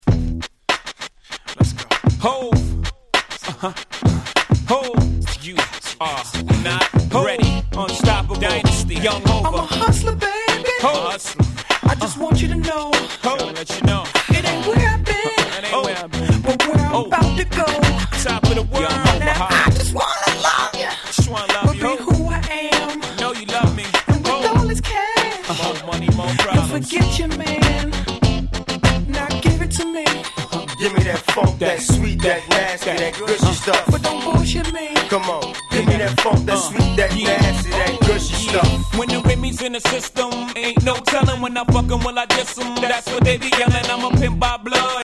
00' Super Hit Hip Hop !!